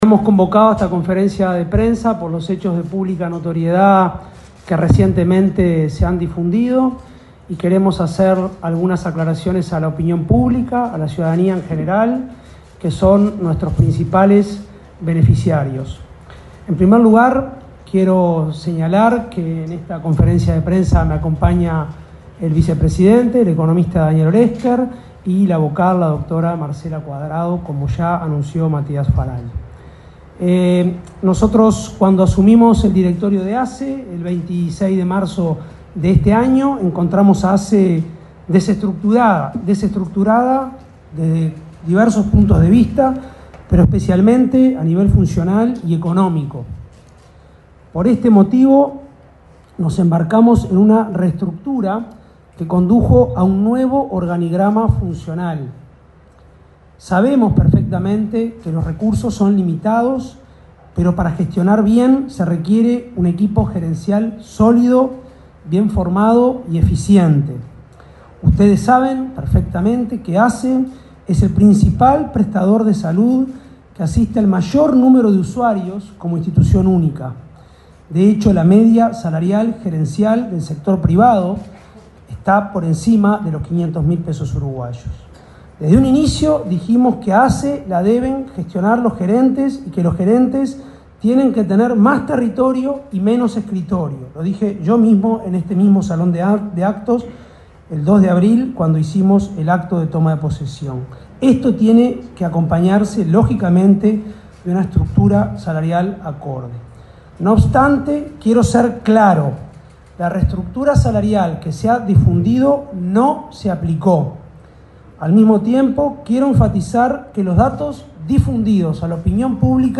Palabras de autoridades de ASSE 11/07/2025 Compartir Facebook X Copiar enlace WhatsApp LinkedIn El presidente de la Administración de los Servicios de Salud del Estado (ASSE), Álvaro Danza; el vicepresidente, Daniel Olesker, y la vocal Marcela Cuadrado, informaron en conferencia de prensa sobre la reestructura del organismo.